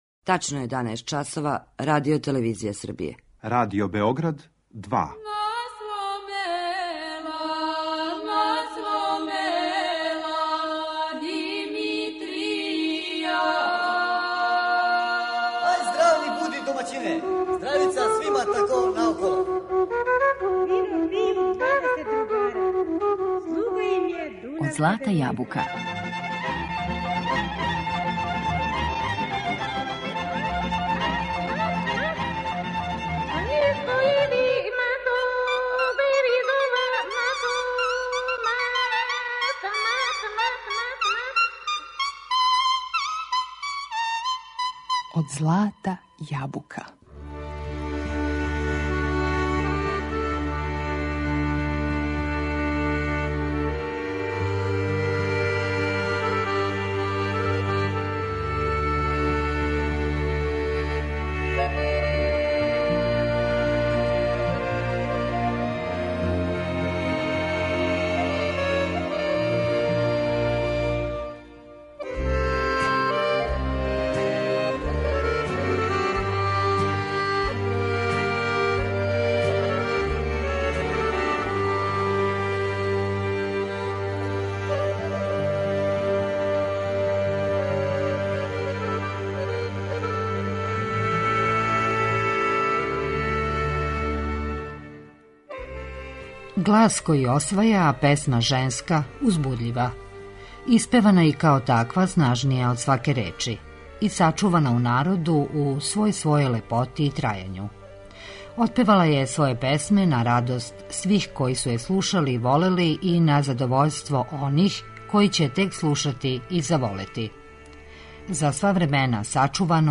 Поседовала је несвакидашњи таленат, а њен глас специфичне боје и начин њене интерпретације прославили су црногорску песму широм некадашње Југославије и широм света.
Данас смо причом и песмом са Ксенијом Цицварић.